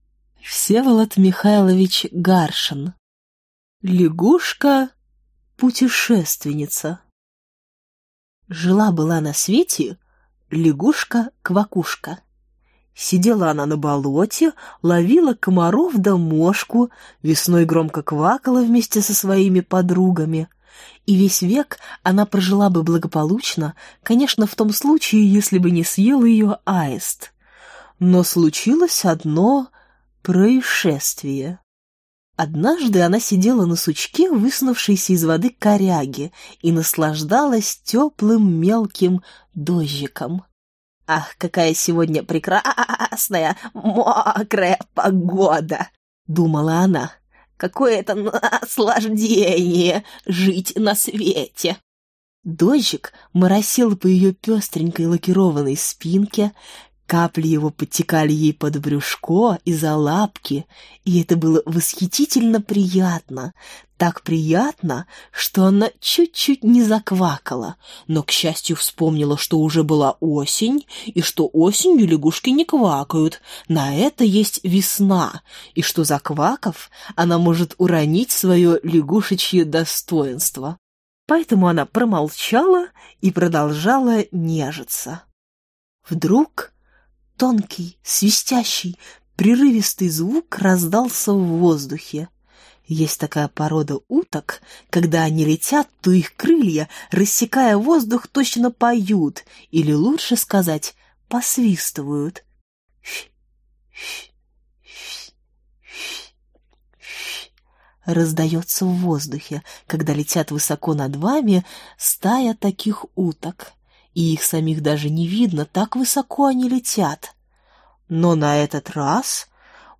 Аудиокнига Сказки о животных | Библиотека аудиокниг